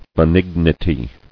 [be·nig·ni·ty]